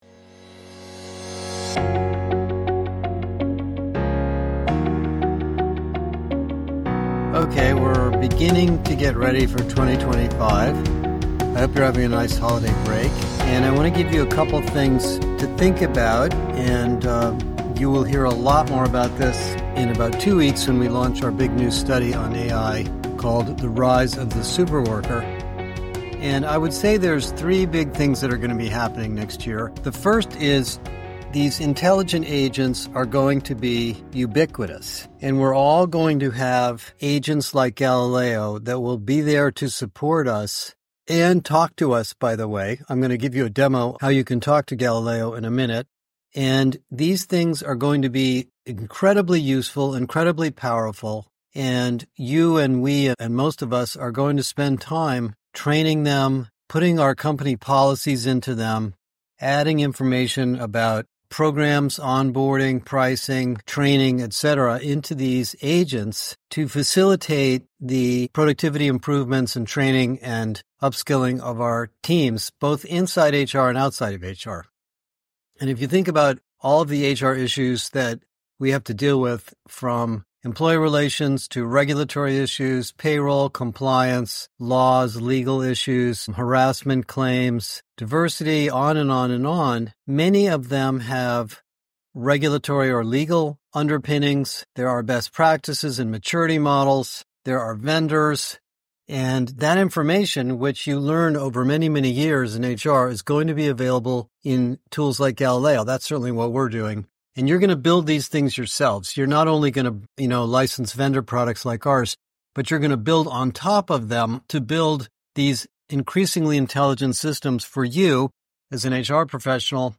Today I give you a sneak preview of Galileo™ as a voice assistant, powered by our upcoming mobile app.